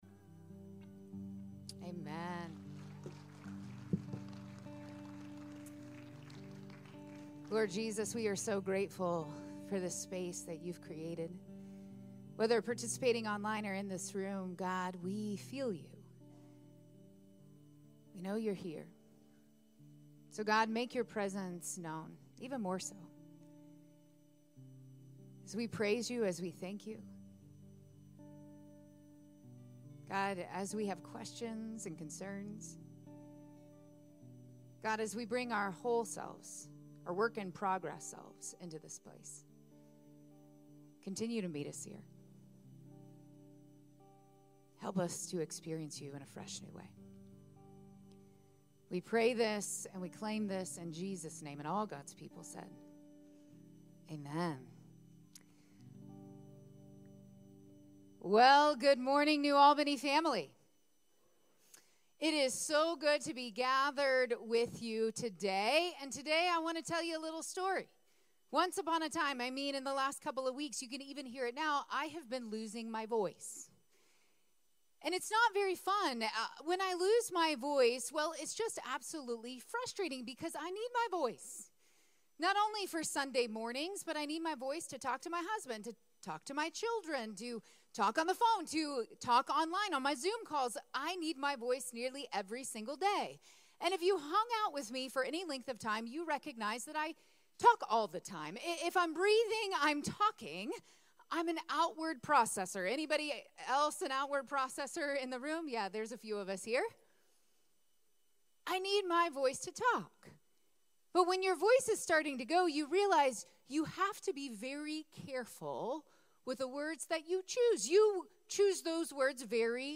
April 21, 2024 Sermon